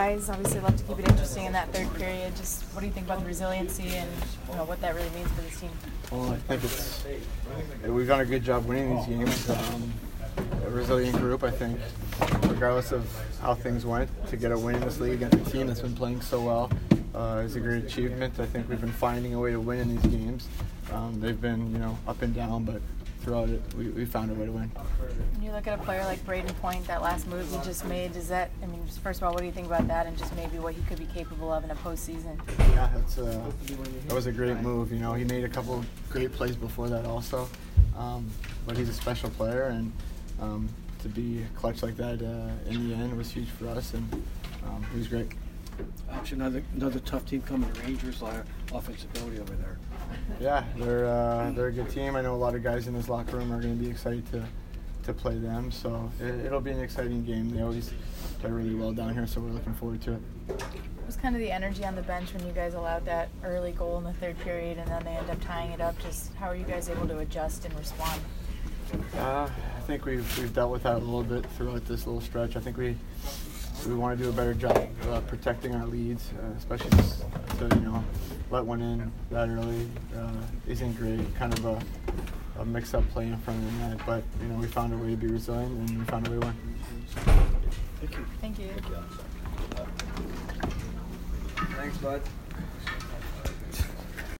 Alex Killorn post-game 3/6